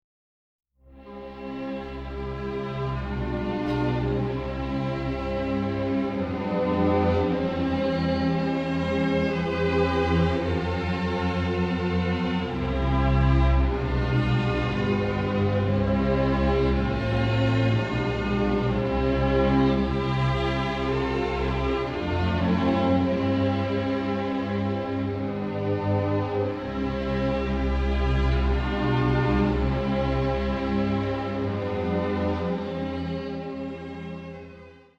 gemischter Chor, Orchester
• die Lieder werden mit besonderer Emotionalität vorgetragen